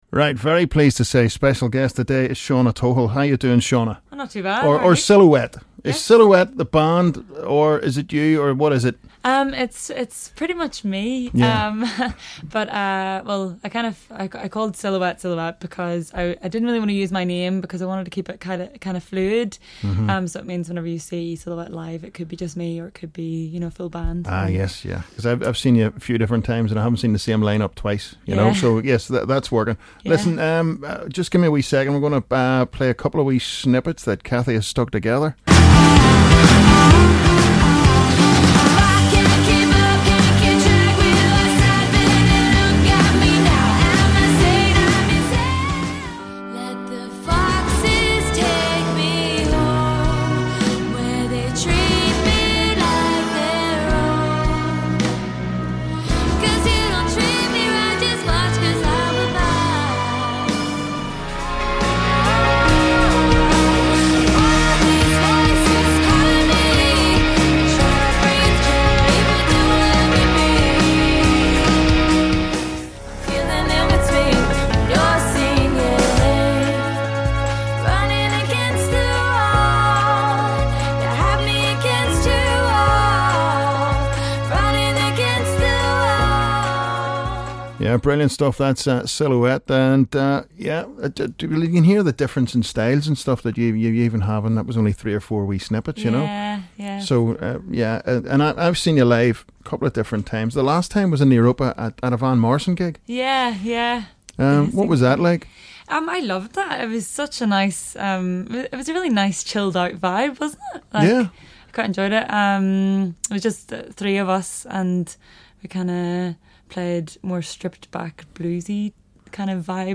Silhouette Interview